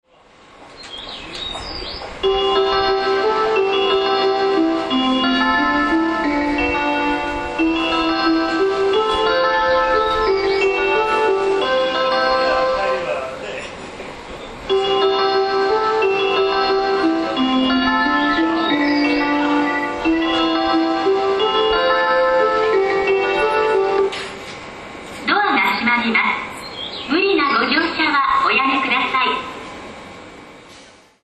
全ホーム旋律は同じですがアレンジが異なります。